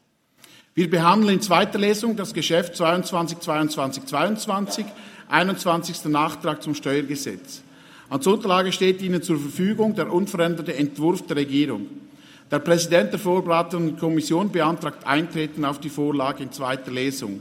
Session des Kantonsrates vom 12. bis 14. Juni 2023, Sommersession